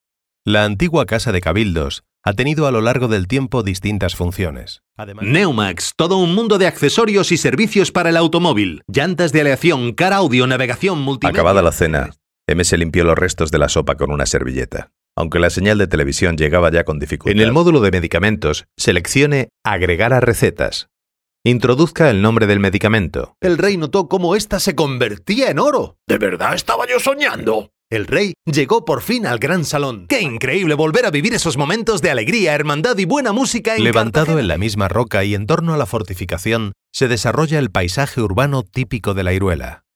Adulto joven, adulto +40, español neutro, español andaluz, Publicidad, Documental, E-learning, Dibujos animados, Jingles, Canciones, Doblaje, Juegos, Presentaciones, Podcasts/internet, Sistema de teléfono, Moderación (on), Audiolibros, estudio propio.
Sprechprobe: Sonstiges (Muttersprache):
Type of voice: Spanish. Tenor, young adult, Adult +40.